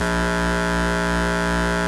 Rare Synthesizer Collection
BUCHLA A#2.wav